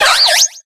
Audio / SE / Cries / SHELMET.ogg